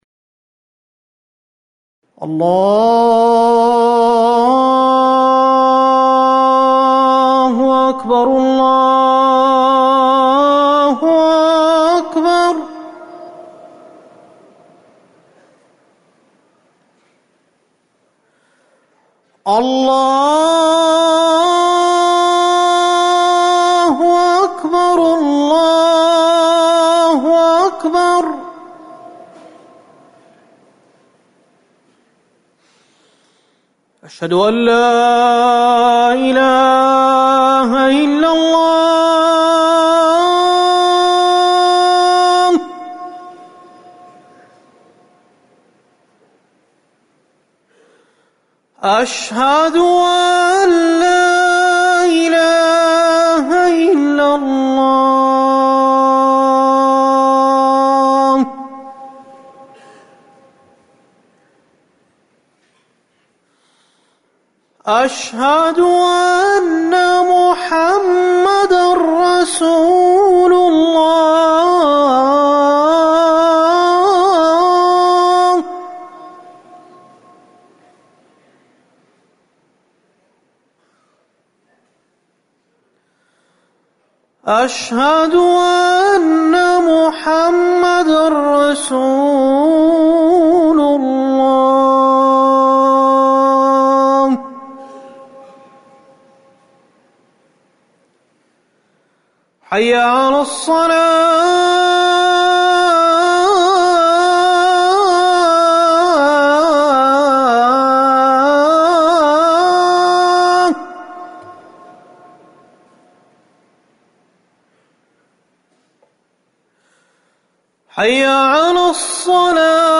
أذان المغرب
المكان: المسجد النبوي